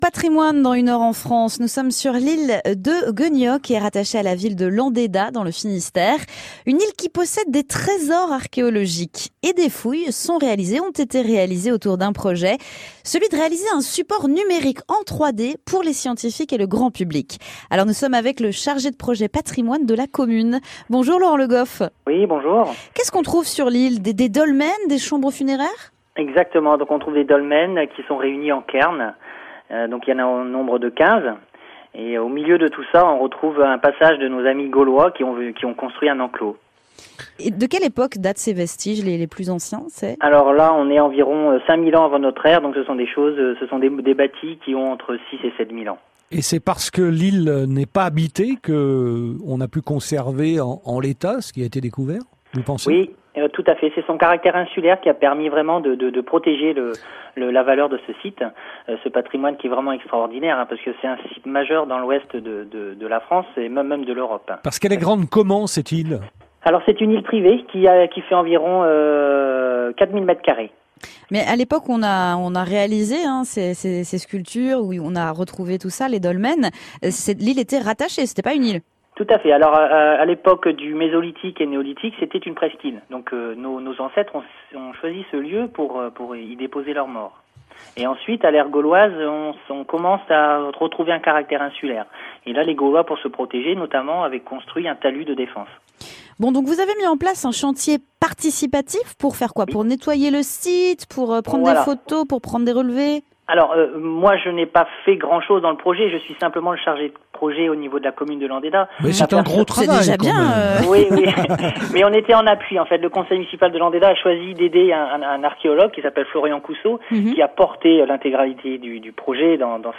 Laurent Le Goff Adjoint au Patrimoine nous parle de l’île Guénioc